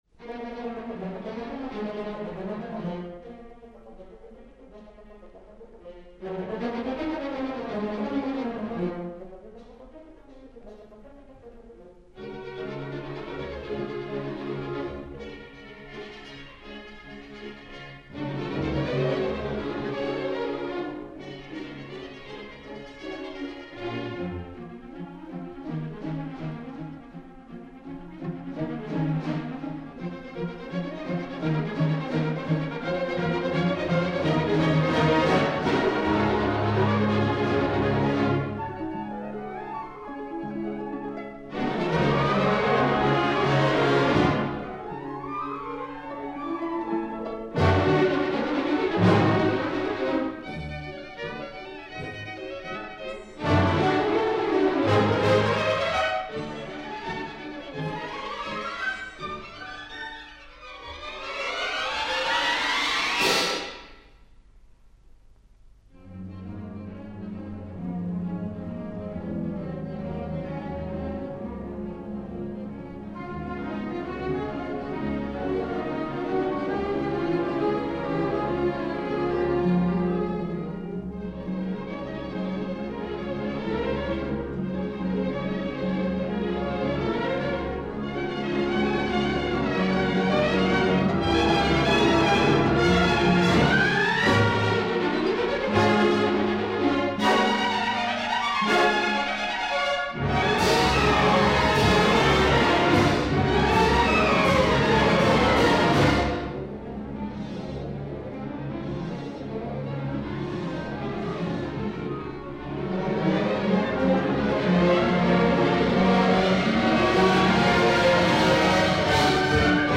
Performed by
Recorded in 1957